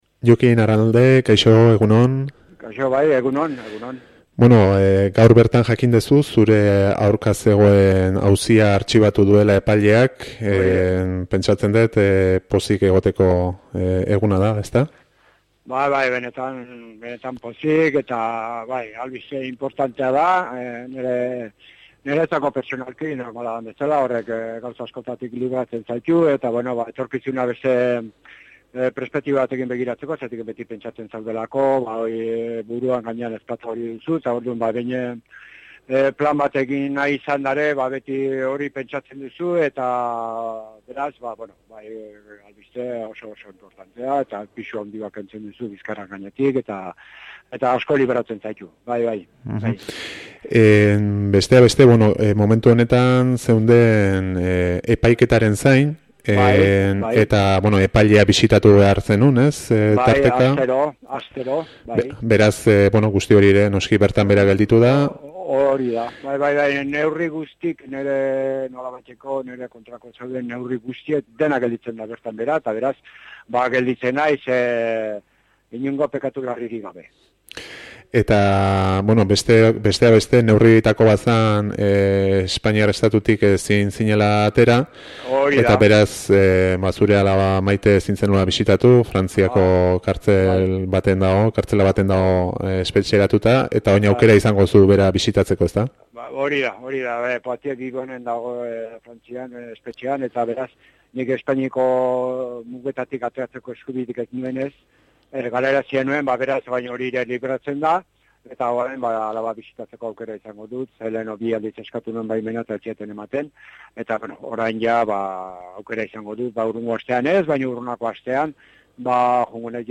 Hona Tolosaldeako atariak egin dio elgarrizketa : http